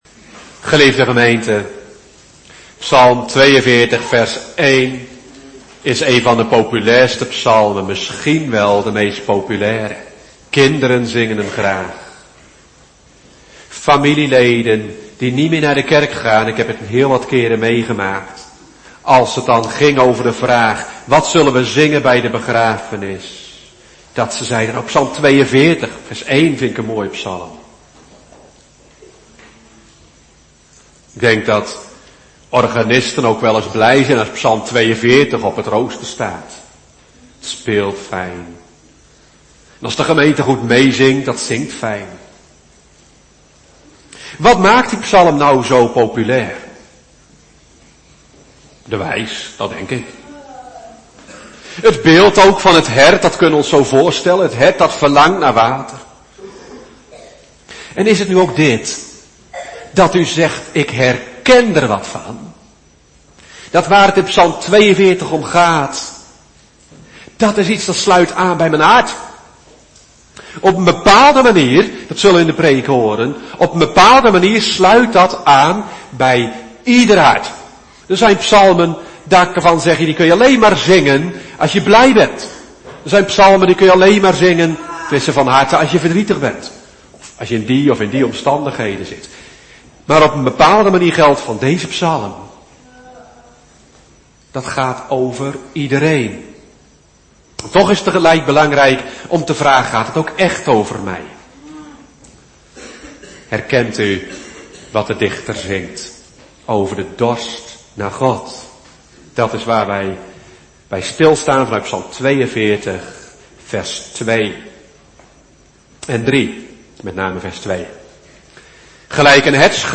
“Dorst naar God” Predikant